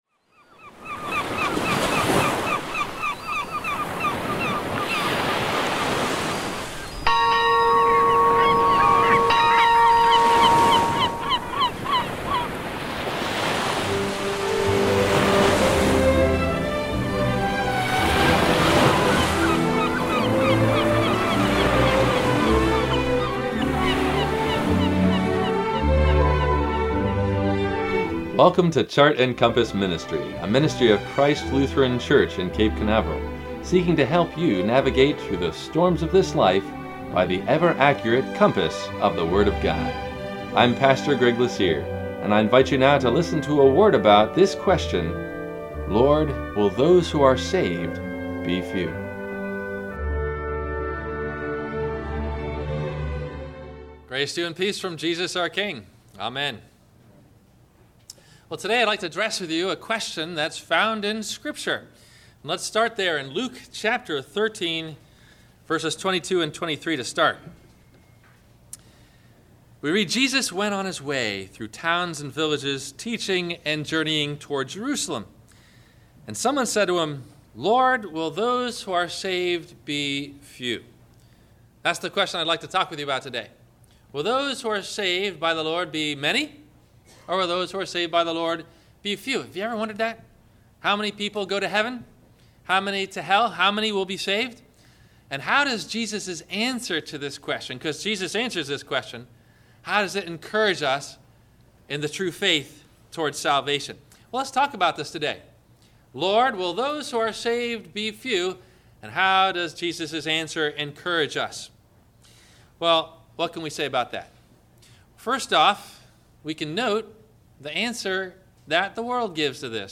Radio Show for Monday March 10 2014 Will-Those-Who-Are-Saved-Be-Few-01-26-14-Sermon